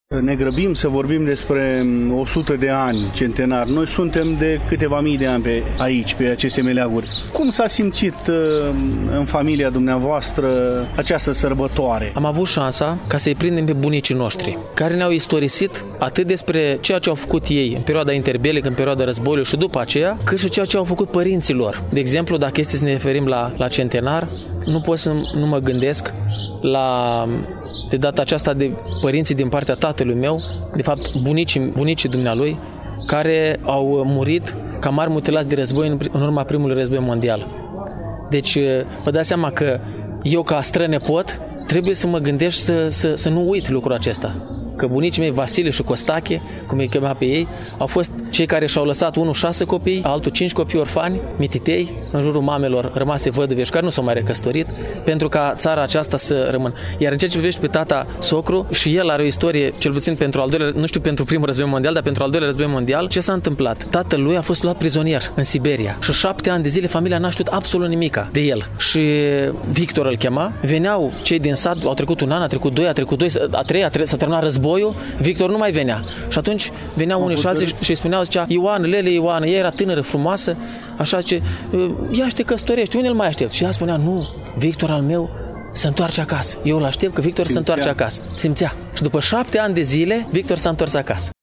Să știți că nu întâmplător pe fundalul intervenției noastre ciripesc câteva păsări ale cerului.